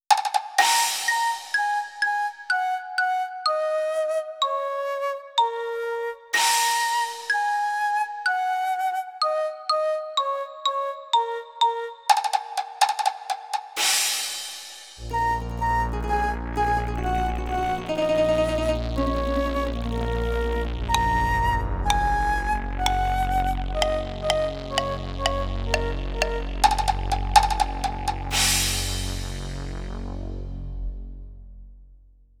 Графический вид в музыкальном секвенсоре